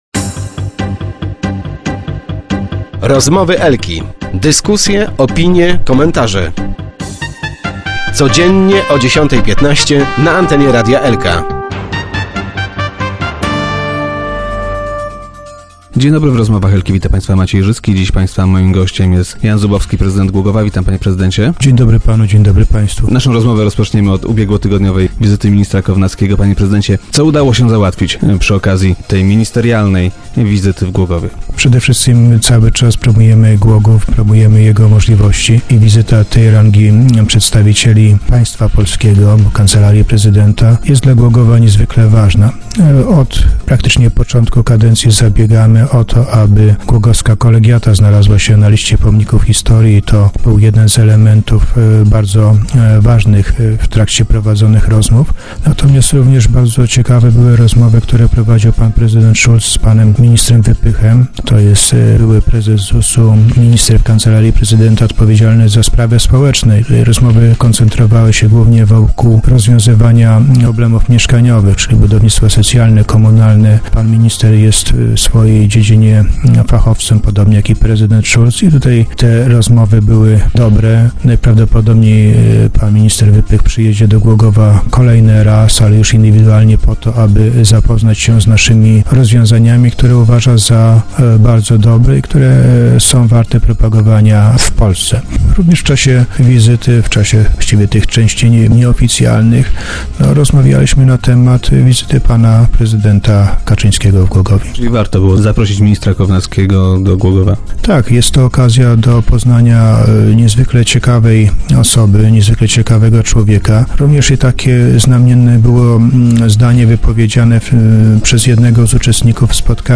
- Niemal w całości wykorzystana zostanie ona na miejskie inwestycje - poinformował Jan Zubowski, prezydent miasta, który był dziś gościem Rozmów Elki.